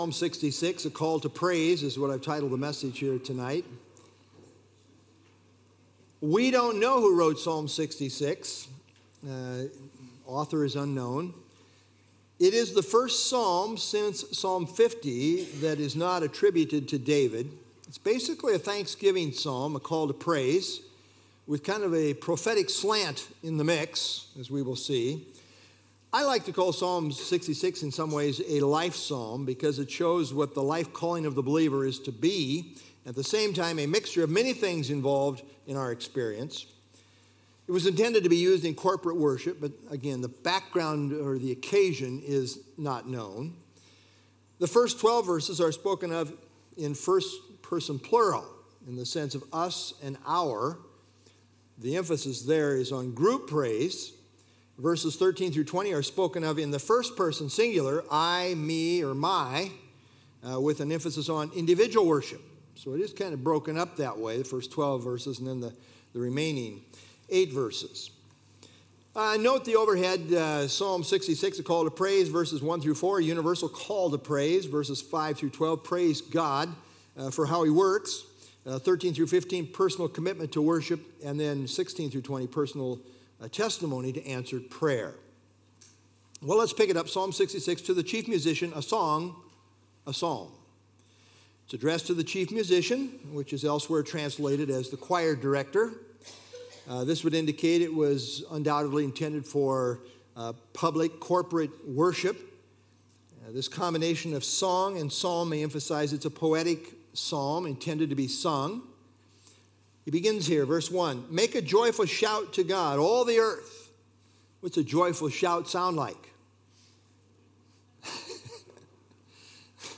( Sunday Evening )